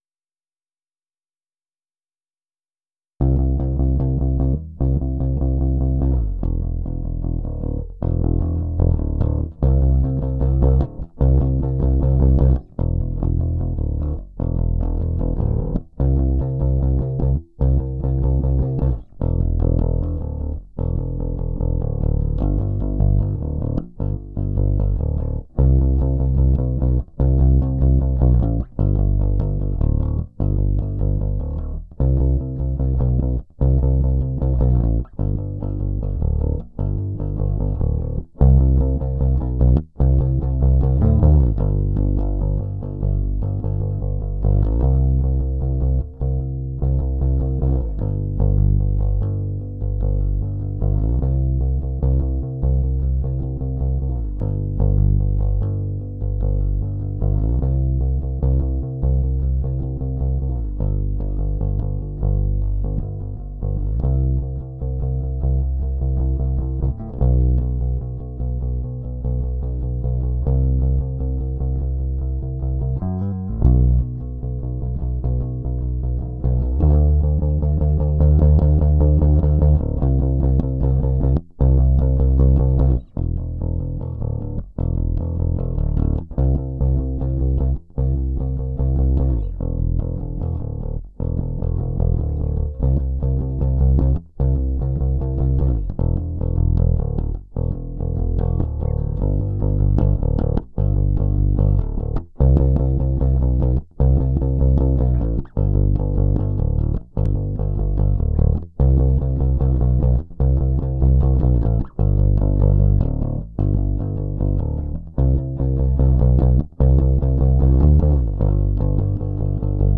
philistine-bass-150bpm.wav